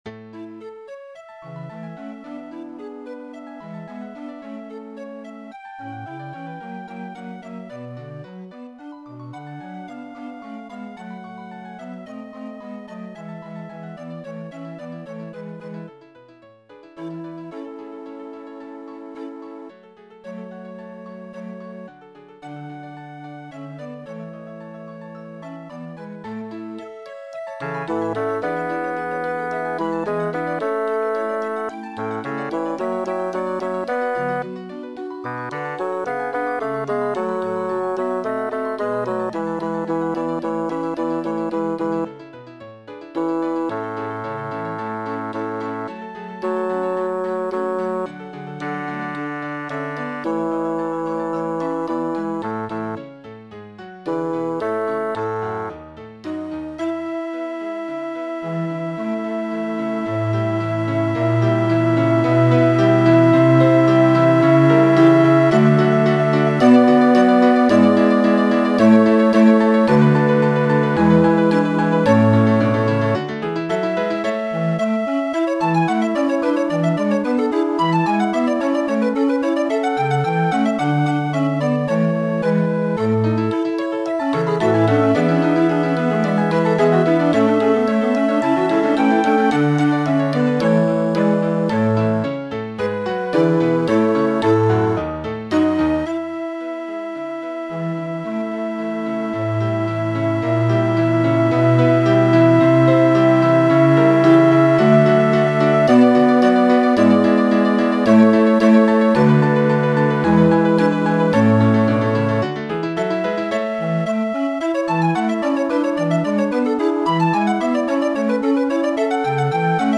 Chorus and soli